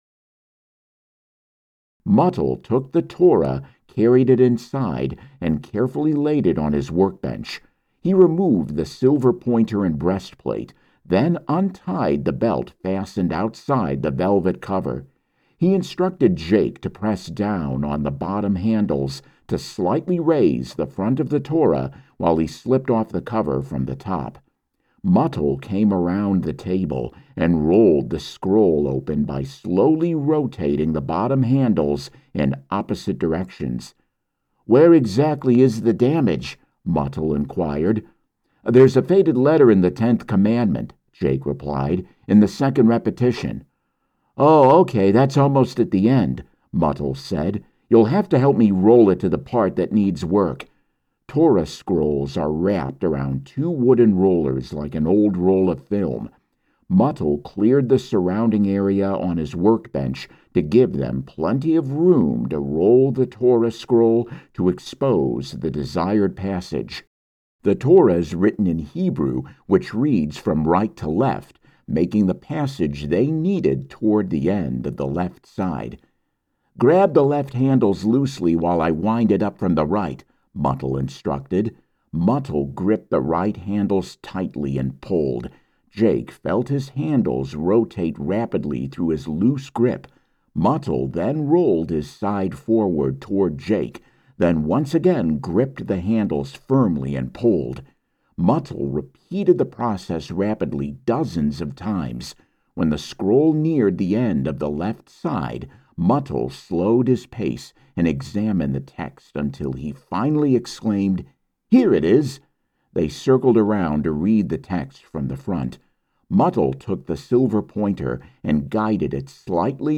Middle Aged
Audiobook